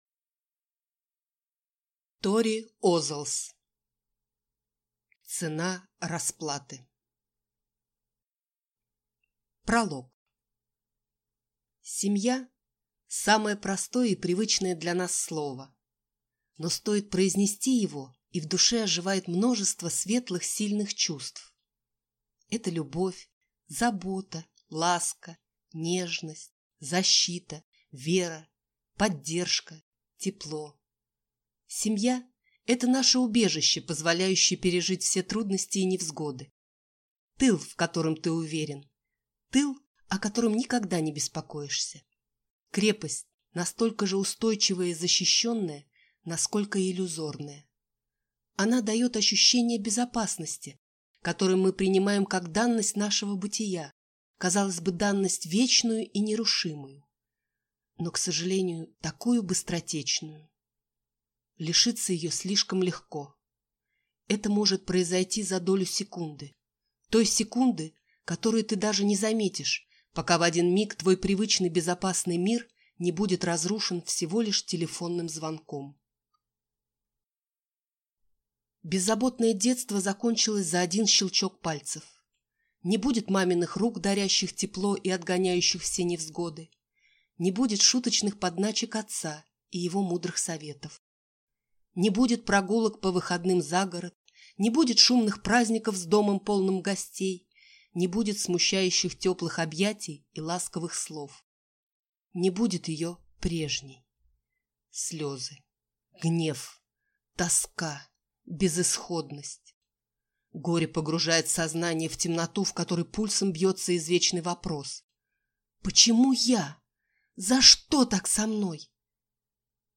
Аудиокнига Цена расплаты - купить, скачать и слушать онлайн | КнигоПоиск